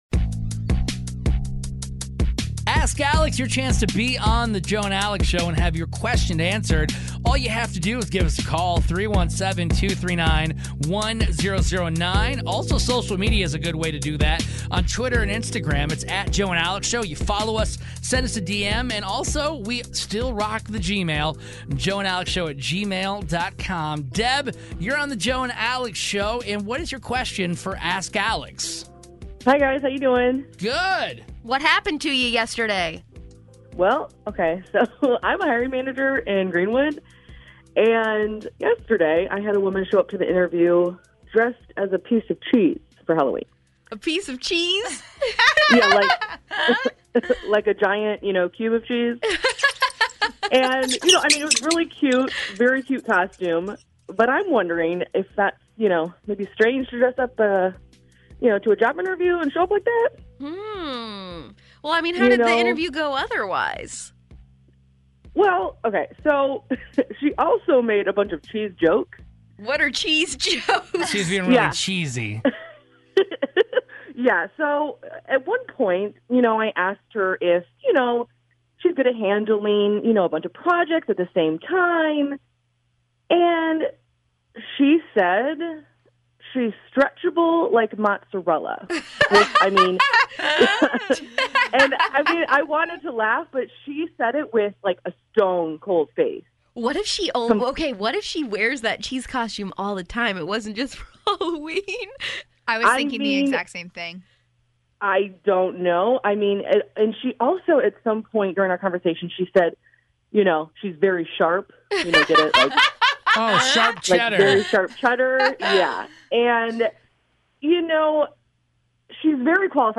This woman went in for a job interview and the girl who interviewed her is calling in to ask about this bizarre encounter.